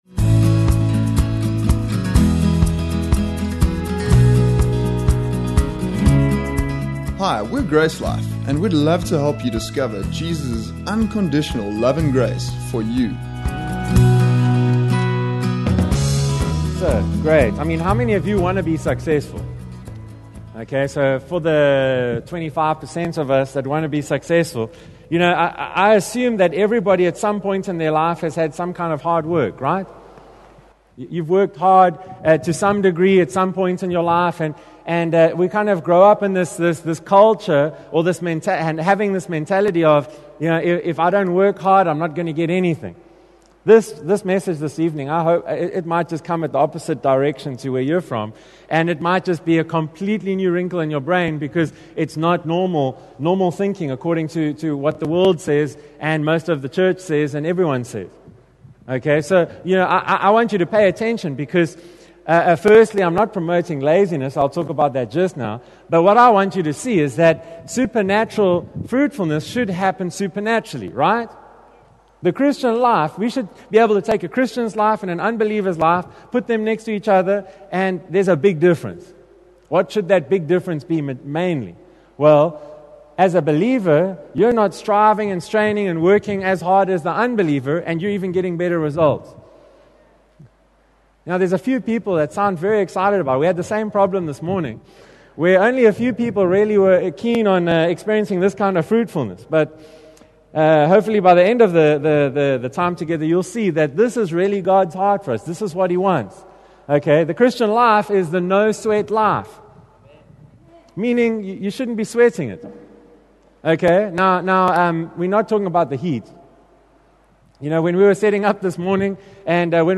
Vision Sunday